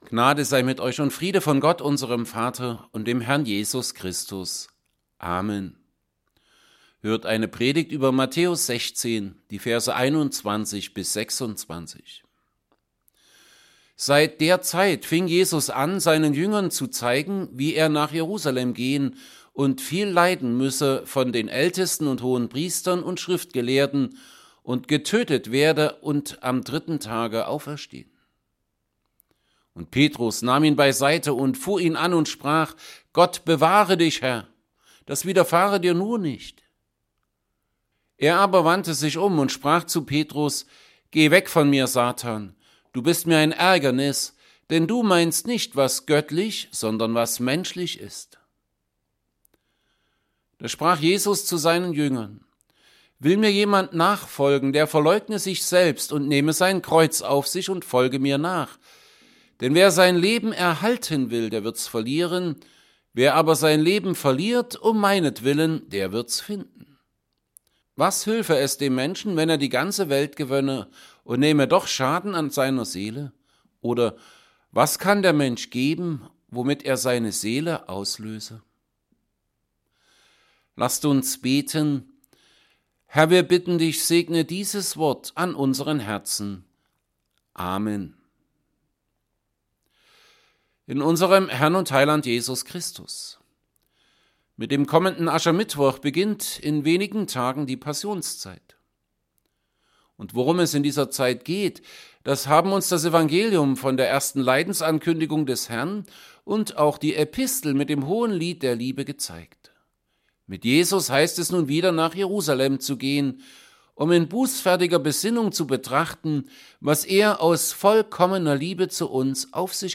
Evangelienpredigten Estomihi 2.